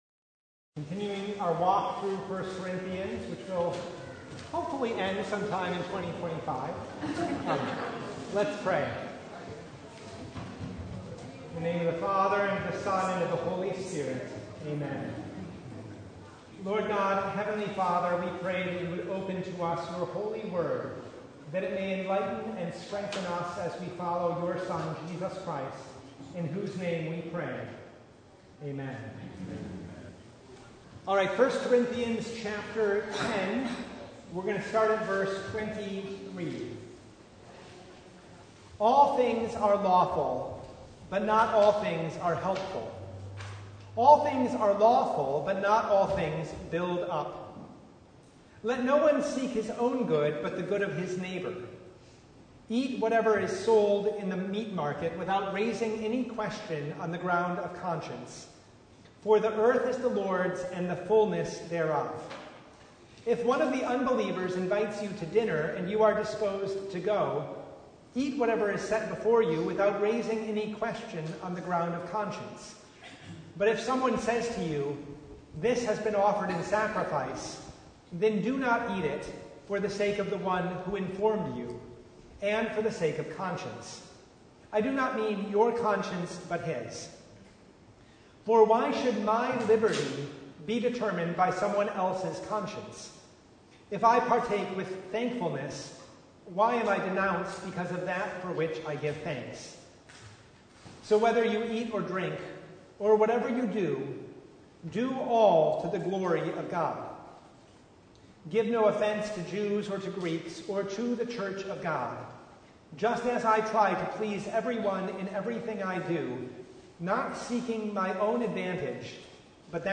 Service Type: Bible Hour Topics: Bible Study